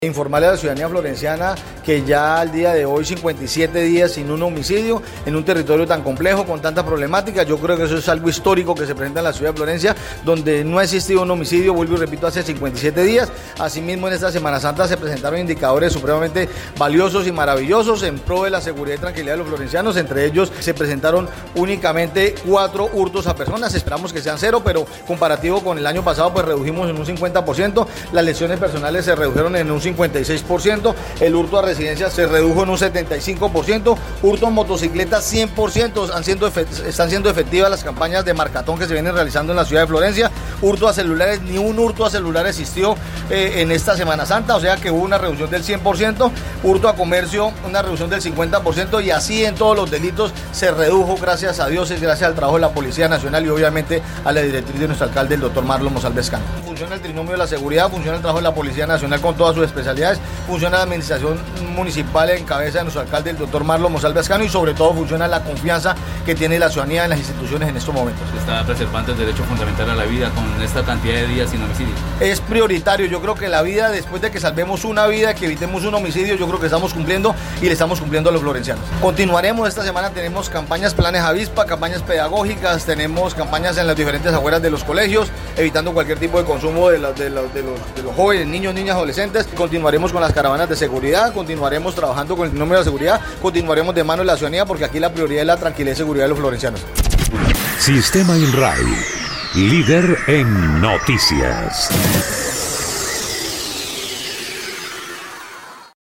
Carlos Mora, secretario de Gobierno Municipal, destacó que estas cifras reflejan el trabajo articulado entre la Administración Municipal y la Policía Nacional, así como el compromiso de la comunidad florenciana, y agregó que, durante la Semana Santa, el balance fue positivo en materia de seguridad.
02_SECGOB_CARLOS_MORA_SEGURIDAD.mp3